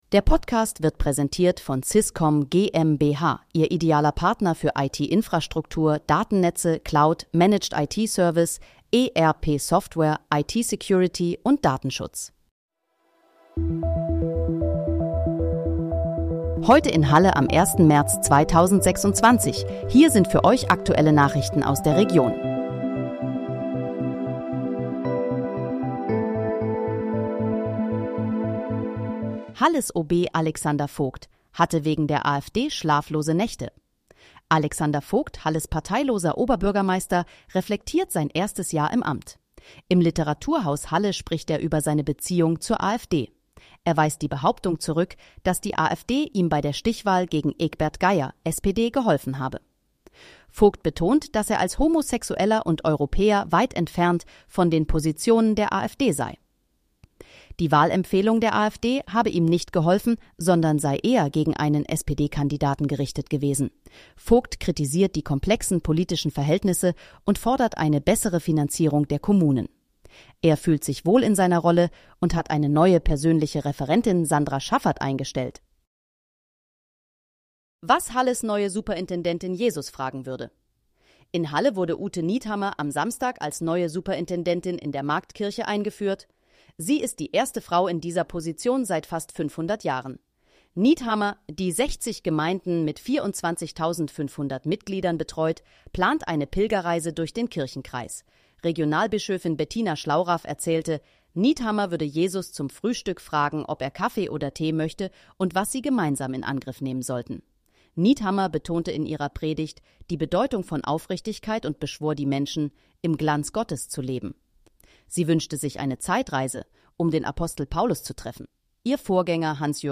Heute in, Halle: Aktuelle Nachrichten vom 01.03.2026, erstellt mit KI-Unterstützung